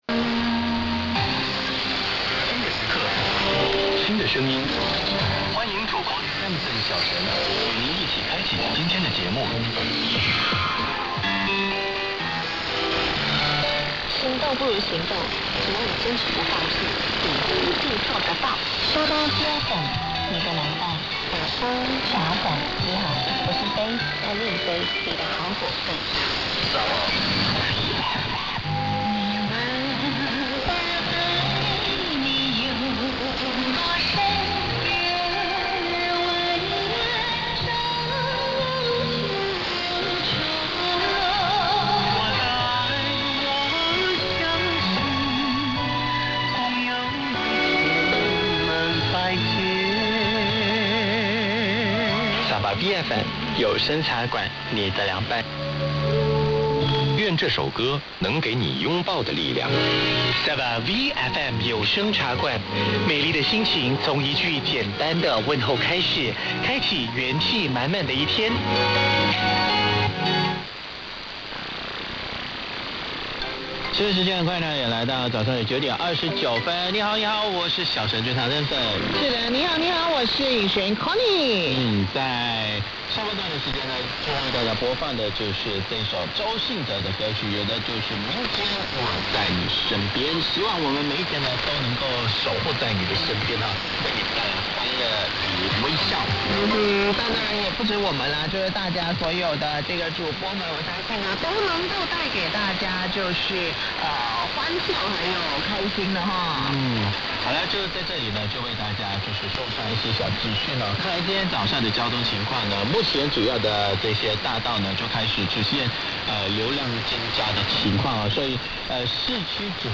These are both coming from the Kajang Transmitting Station near Kuala Lumpur.
Sabah V FM April 2, 2026 on 11885 kHz at 0128 UTC: